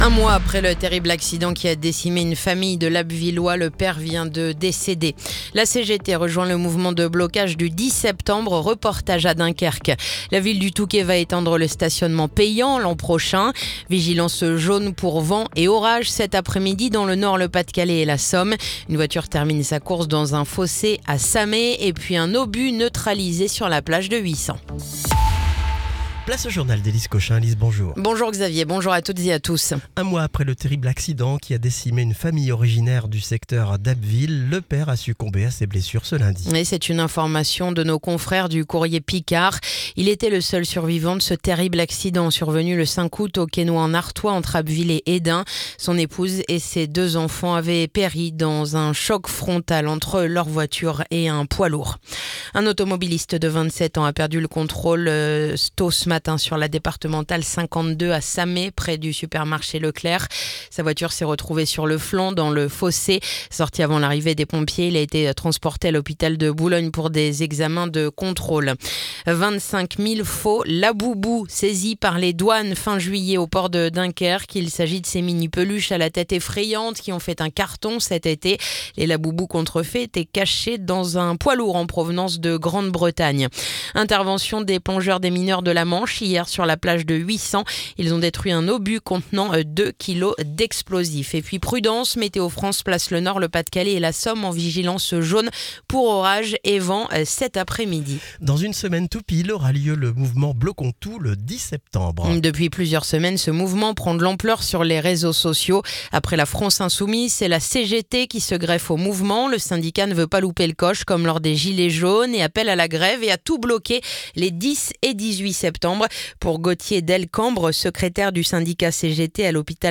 Le journal du mercredi 3 septembre